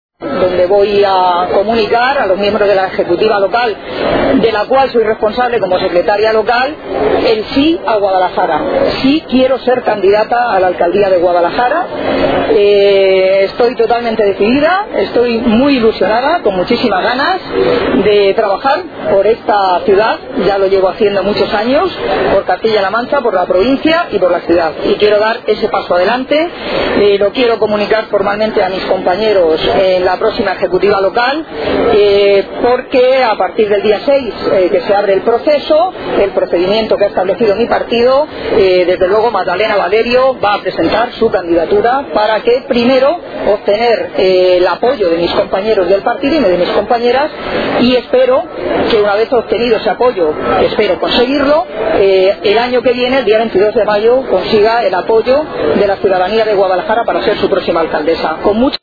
Cortes de audio de la rueda de prensa
Magdalena_Valerio_Corte_1.mp3